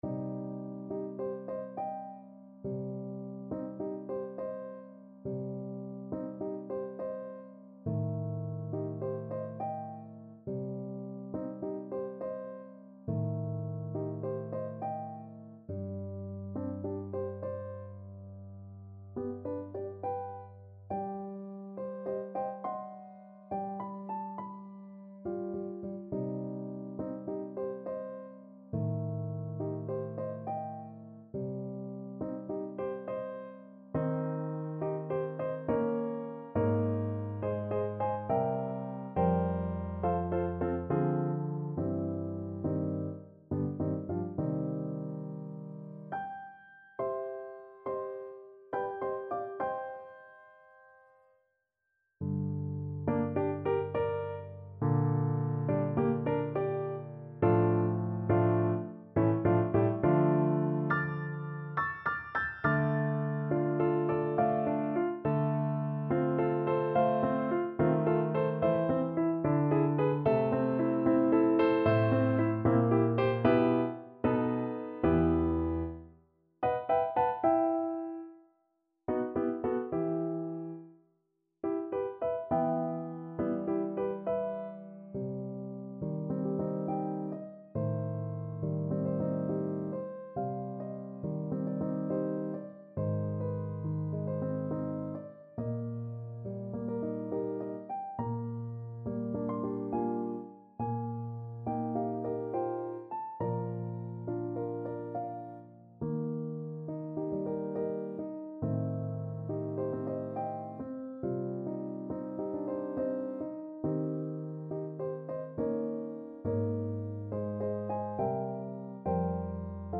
Play (or use space bar on your keyboard) Pause Music Playalong - Piano Accompaniment Playalong Band Accompaniment not yet available reset tempo print settings full screen
C minor (Sounding Pitch) D minor (Trumpet in Bb) (View more C minor Music for Trumpet )
Andante =69
3/8 (View more 3/8 Music)
Classical (View more Classical Trumpet Music)